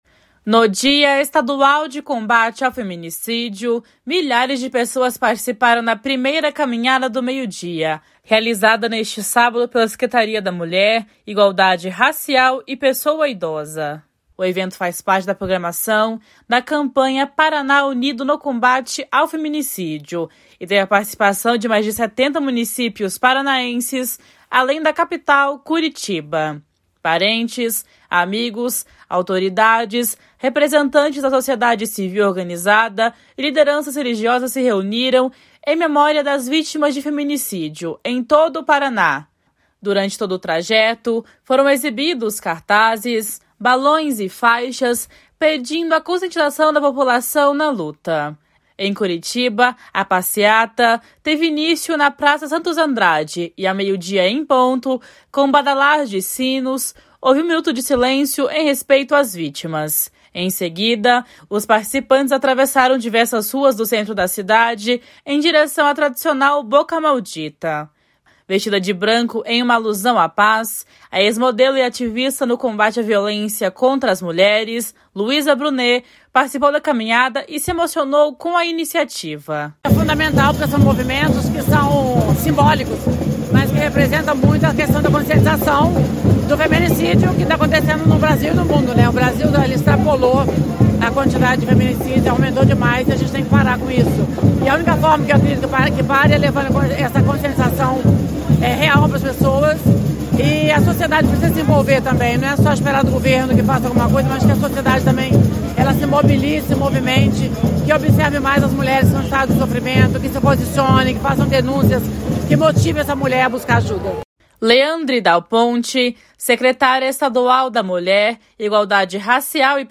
// SONORA LUIZA BRUNET //